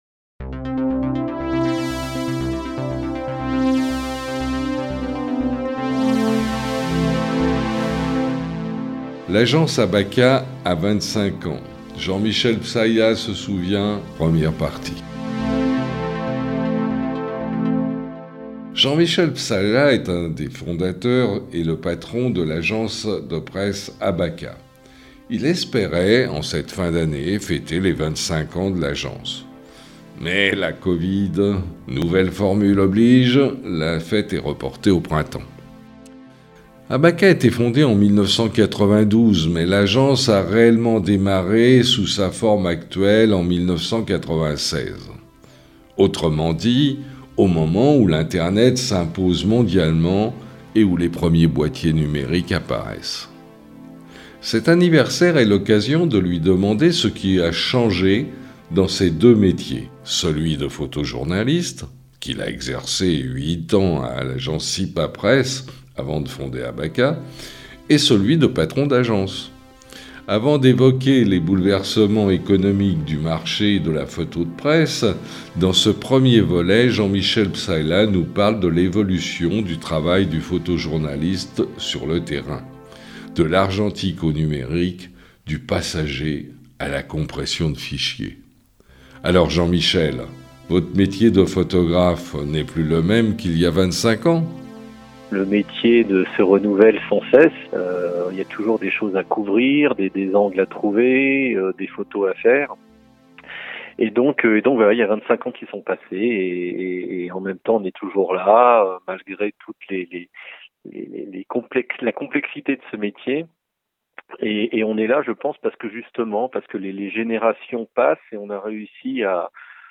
Cette interview a été réalisée par téléphone le vendredi 3 décembre 2021